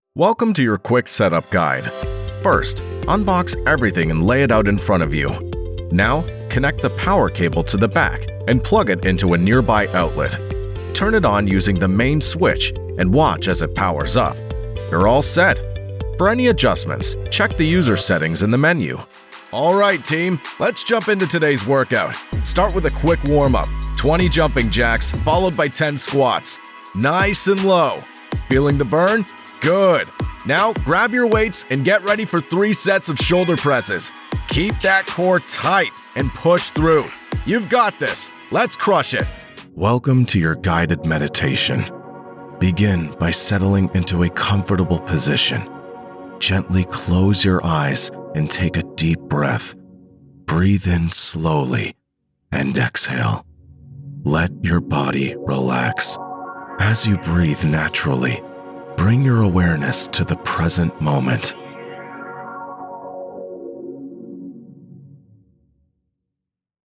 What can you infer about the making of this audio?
All recordings are done in my professional sound-treated vocal booth.